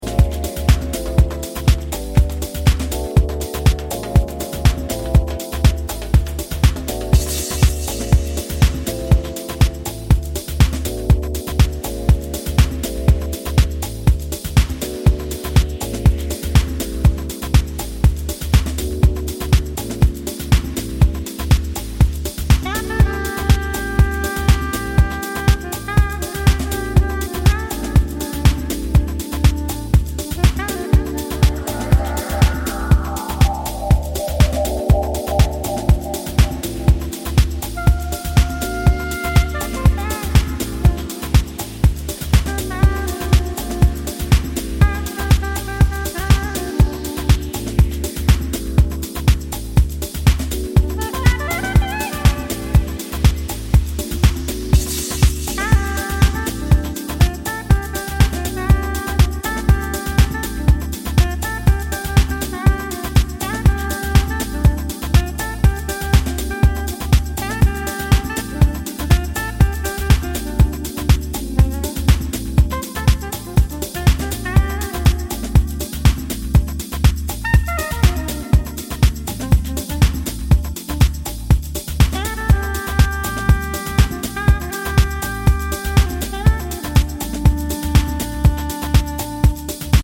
deep house
soulful vocals, jazzy harmonies, funky bass lines